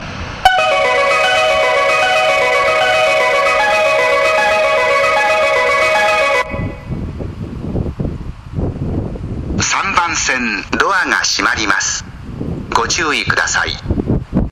スピーカーは１〜４番線ともに小ボスが使用されており音質はとてもいいと思います。
発車メロディー途中切りです。